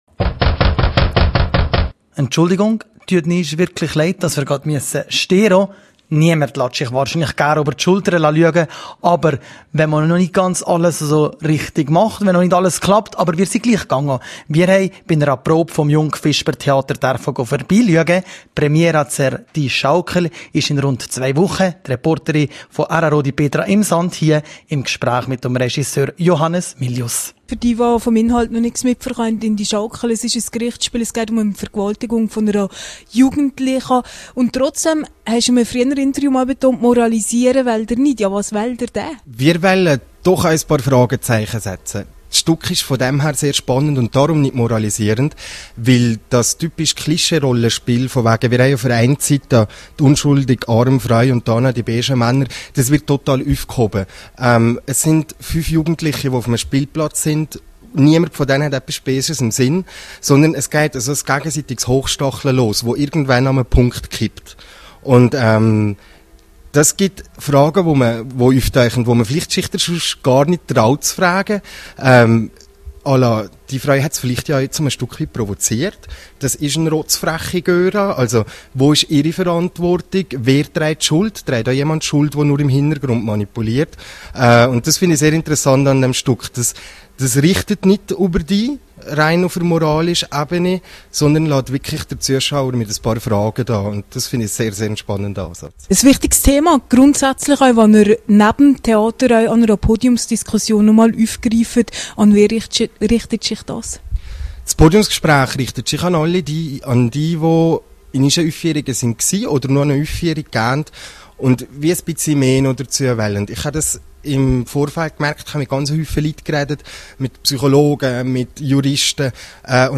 rro-Interview